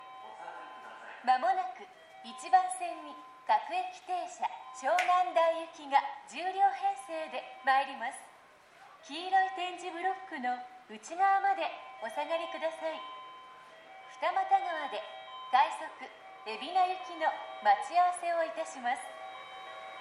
この駅では発車放送・接近放送が設置されています。
１番線SO：相鉄線
接近放送各駅停車　湘南台行き接近放送です。